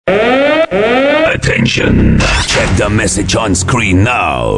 Kategori Alarm